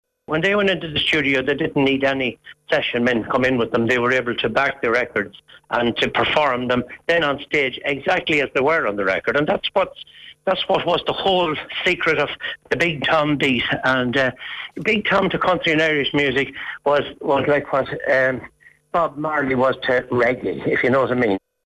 Declan Nerney says Tom and his band The Mainliners created a unique sound: